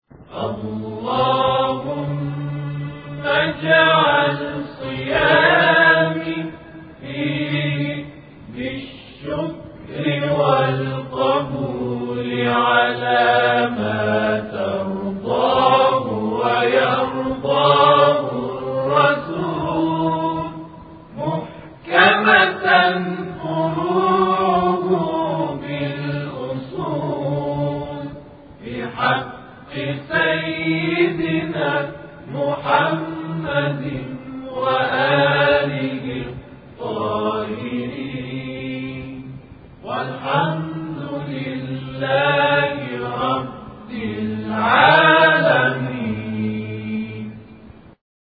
همخوانی دعای روز سی ام ماه مبارک رمضان + متن و ترجمه
در این محتوا، متن کامل دعای روز سی‌ام ماه مبارک رمضان به همراه ترجمه روان فارسی و صوت همخوانی ادعیه با صدایی آرامش‌بخش را دریافت کنید.